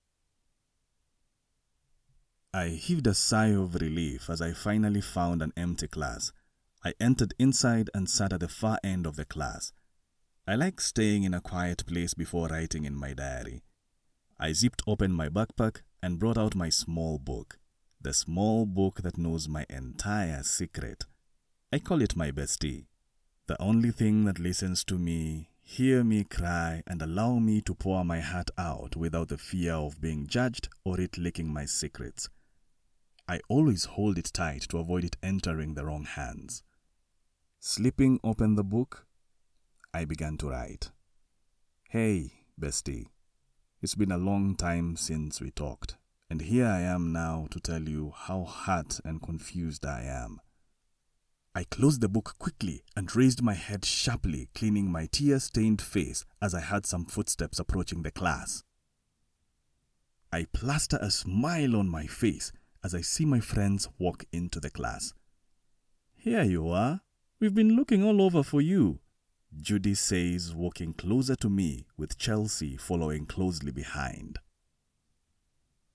Englisch (afrikanisch)
Erzählung
TiefNiedrig
ReifenWarmLustigAutorisierendEmotional